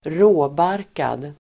Uttal: [²r'å:bar:kad]